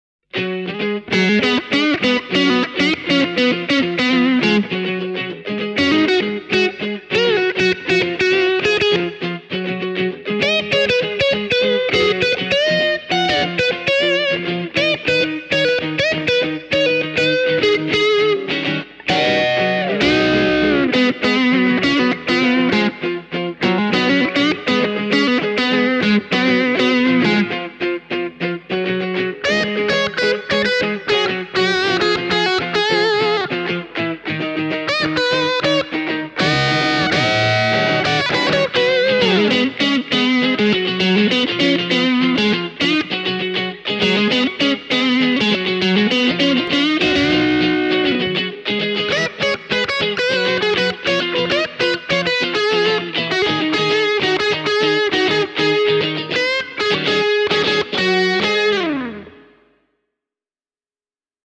The stacked coil pickups are free from hum and buzz, and they give you a fine, slightly ”pre-compressed” sound that sits well in any mix. The JB-humbucker offers just the right amount of ”push” to complement the neck and middle pickups perfectly.
This is noise-free high gain heaven with a healthy dose of bottom end punch and a chunky mid-range.
The demo track features the trio in the following order: Sienna Sunburst –> Ebony Transparent –> Three-Tone Sunburst. Each guitar plays both the rhythm as well as the lead parts of its section: